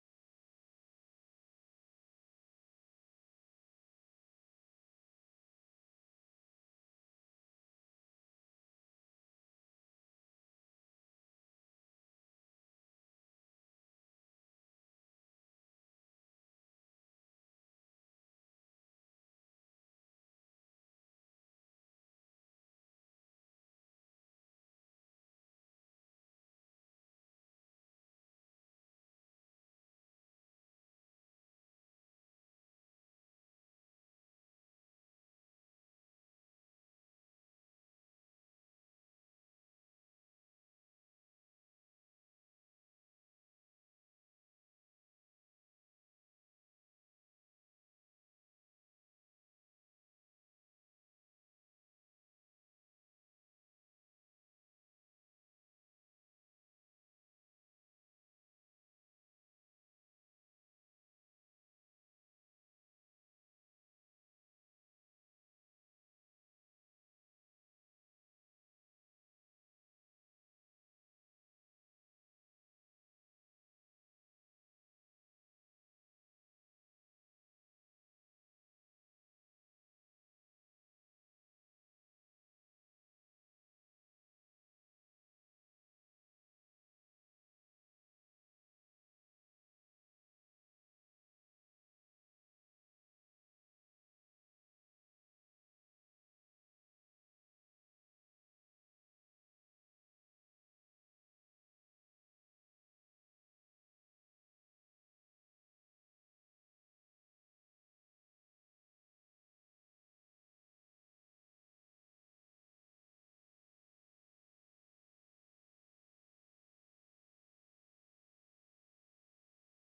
Jesus only – Forward? Sermon
Jesus-Only-Forward-Sermon-Audio-CD.mp3